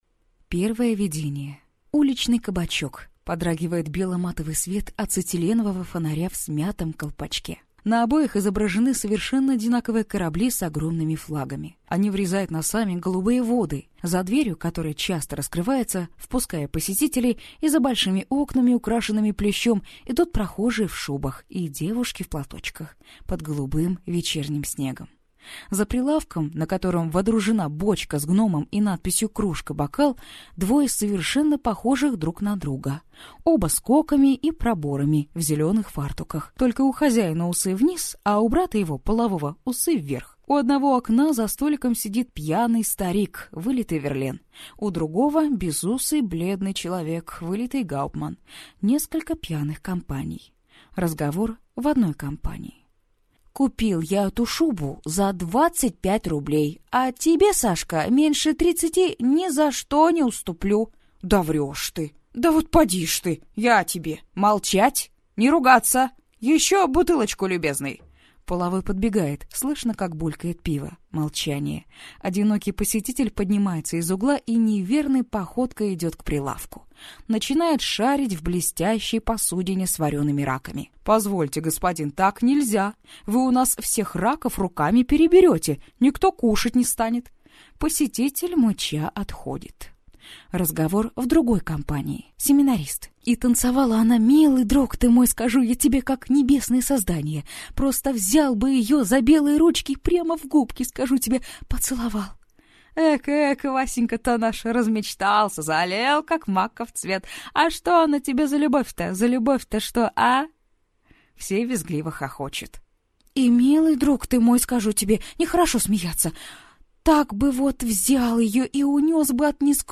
Аудиокнига Незнакомка | Библиотека аудиокниг